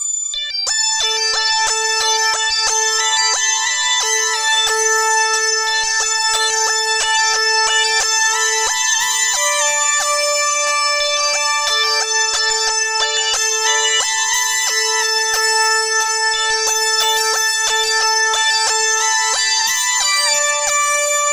Synth 42.wav